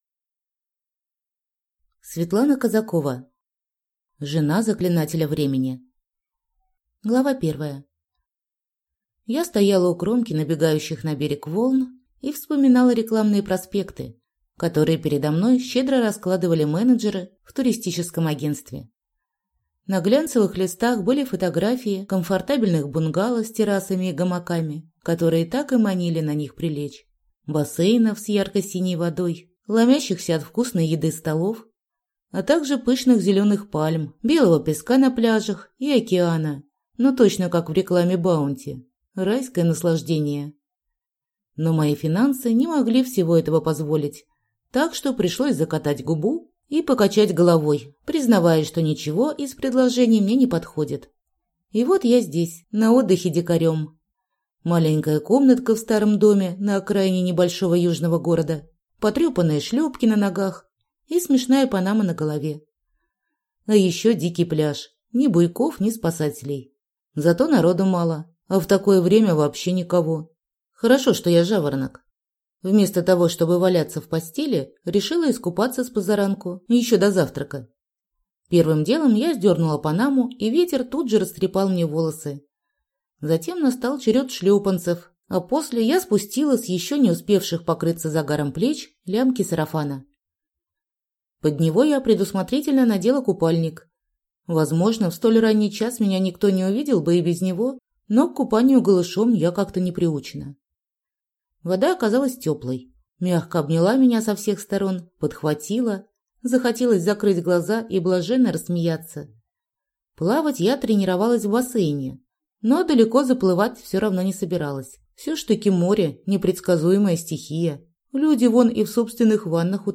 Аудиокнига Жена заклинателя времени | Библиотека аудиокниг